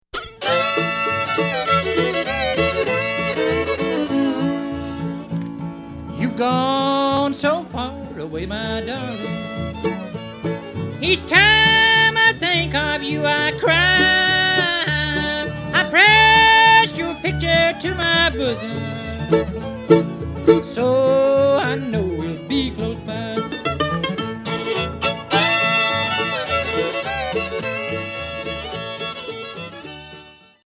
Bluegrass